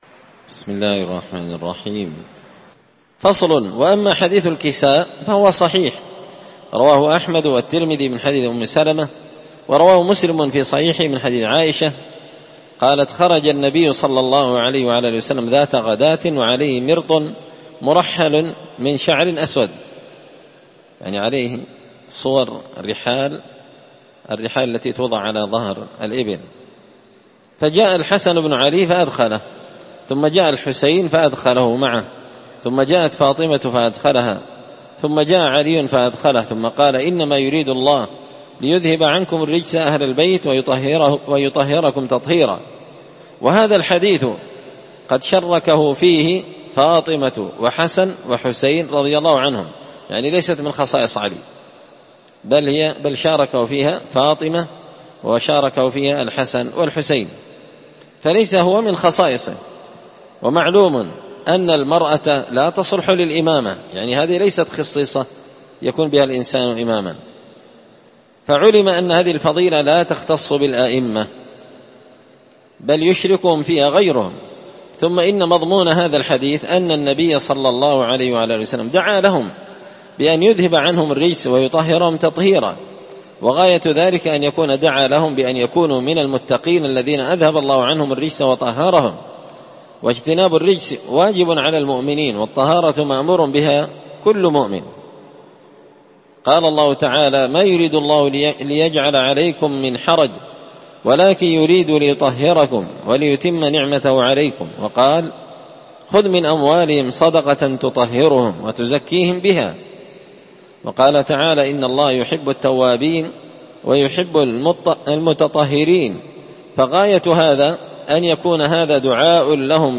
الأربعاء 11 ذو القعدة 1444 هــــ | الدروس، دروس الردود، مختصر منهاج السنة النبوية لشيخ الإسلام ابن تيمية | شارك بتعليقك | 28 المشاهدات